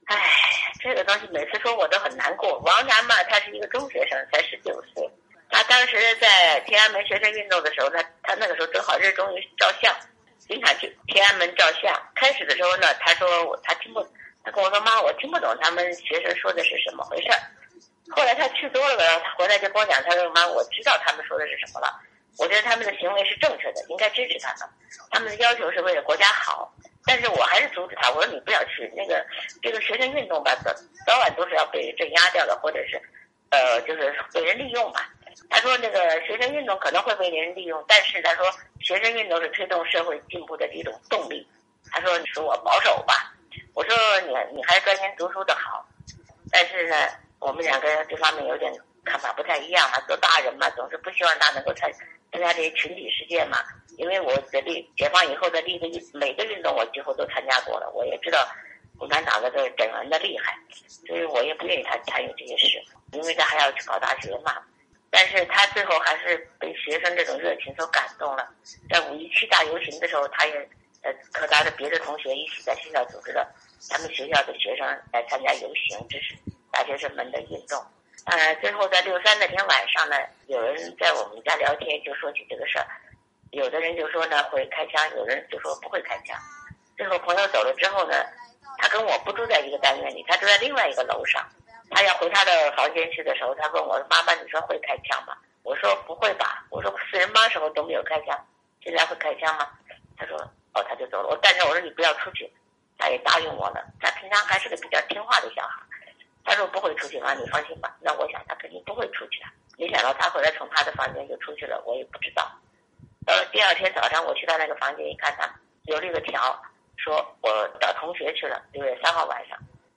（根据采访录音整理，受访者观点不代表美国之音）